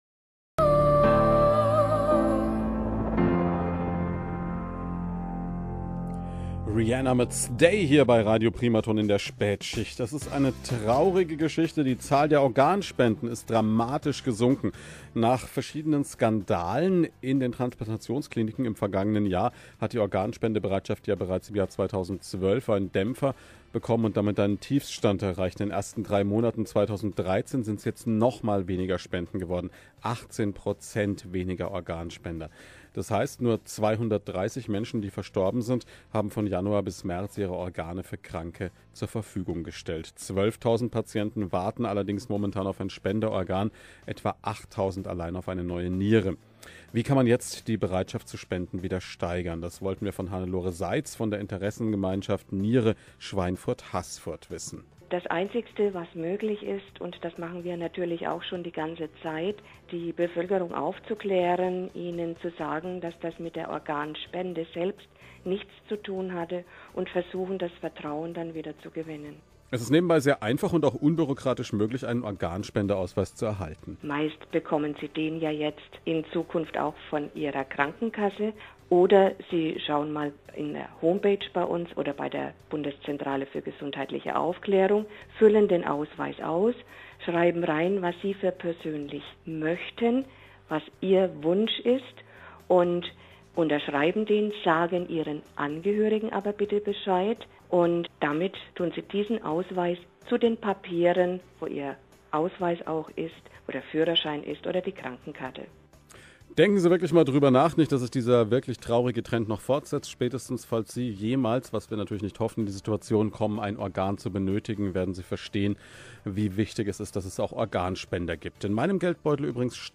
Interview zu den neuen Zahlen Organspende - Wie kann ich die Spendenbereitschaft erhöhen?